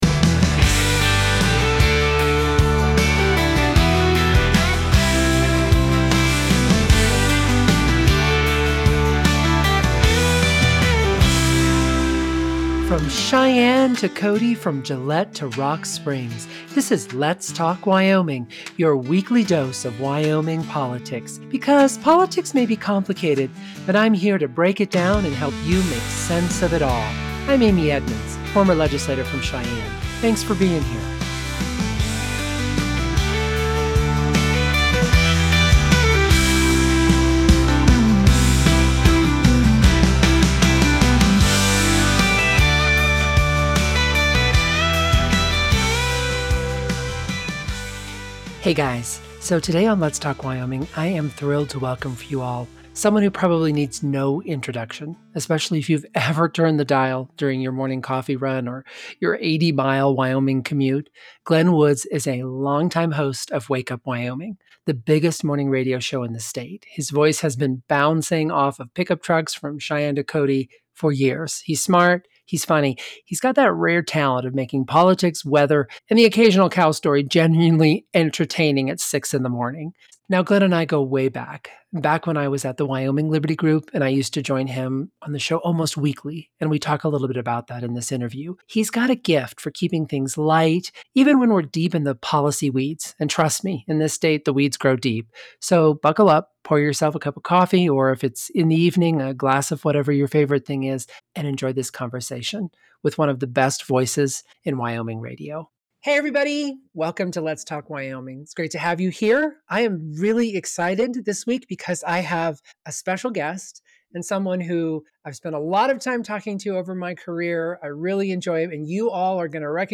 an interview with Wyoming's